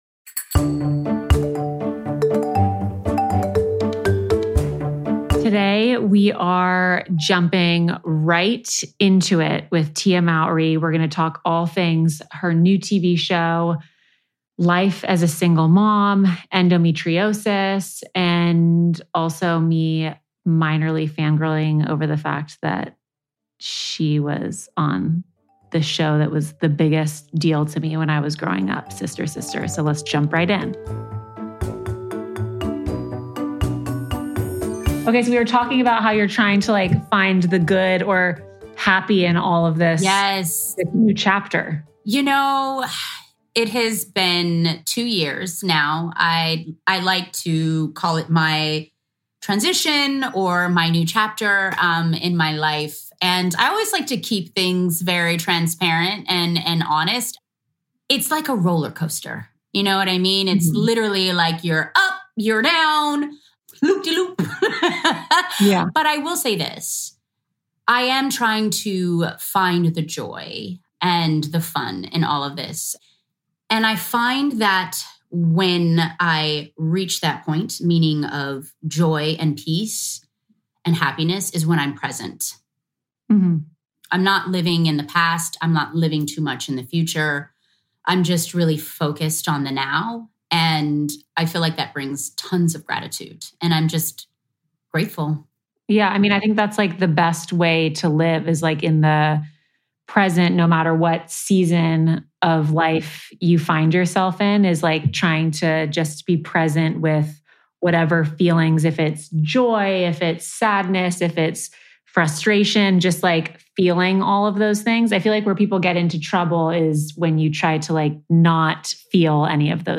Through this heartfelt conversation, Tia reminds us that embracing discomfort is key to personal growth and that joy is found in the present moment.